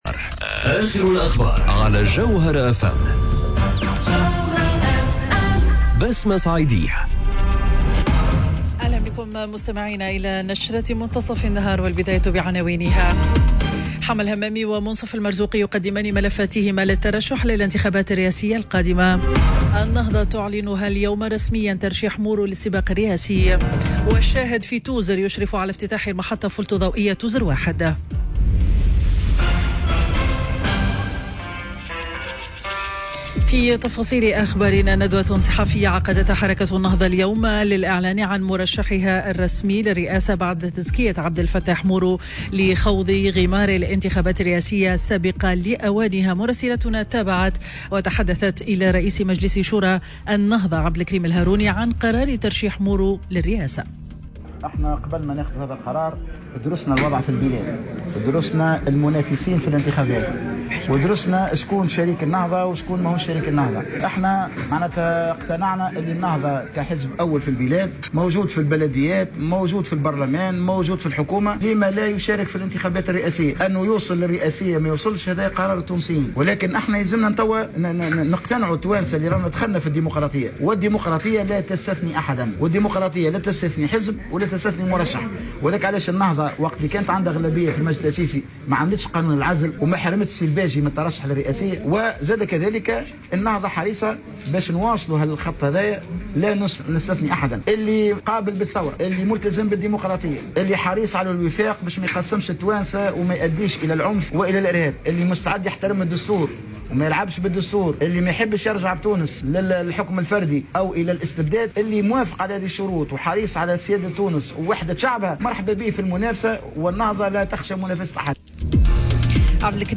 نشرة أخبار منتصف النهار ليوم الإربعاء 7 أوت 2019